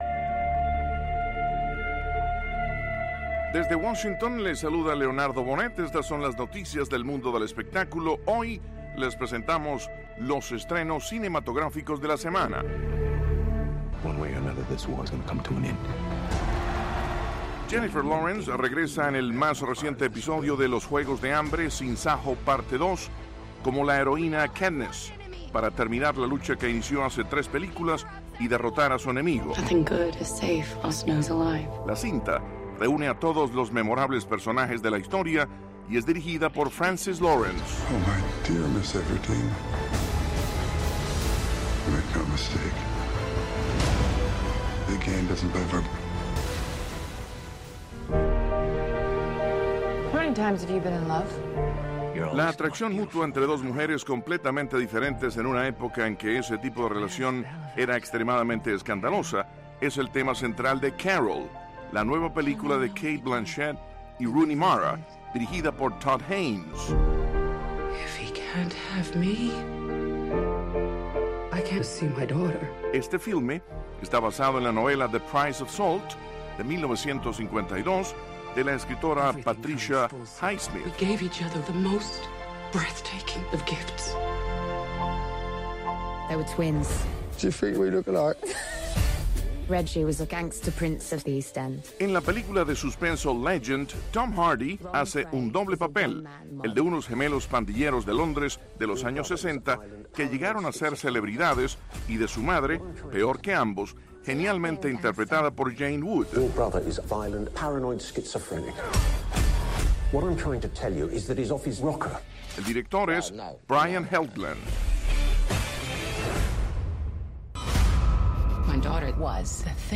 Noticias del entretenimiento - 5:30pm